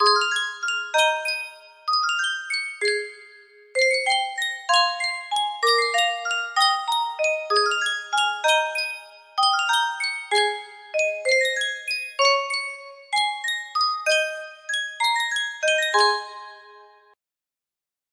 Sankyo Music Box - Love's Old Sweet Song VJS music box melody
Full range 60